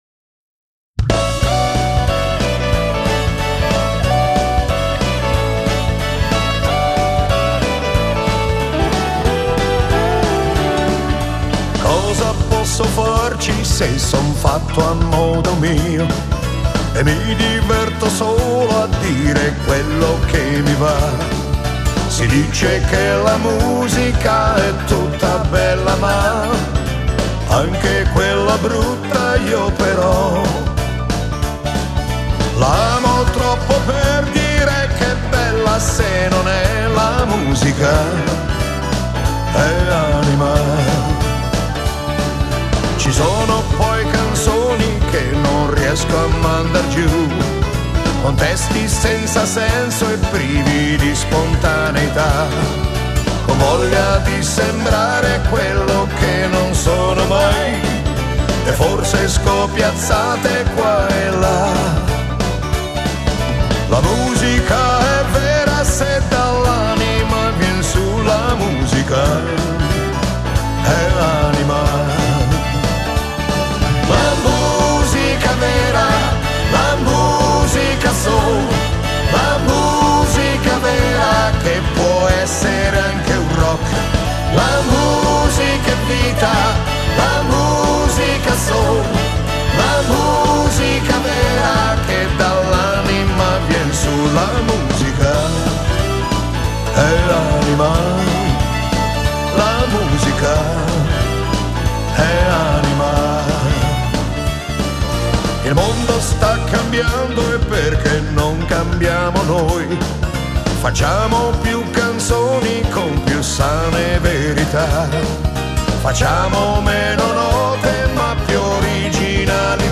Genere: Moderato rock